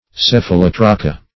Search Result for " cephalotrocha" : The Collaborative International Dictionary of English v.0.48: Cephalotrocha \Ceph`a*lot"ro*cha\, n. [NL., fr. Gr. kefalh` head + ? wheel.]
cephalotrocha.mp3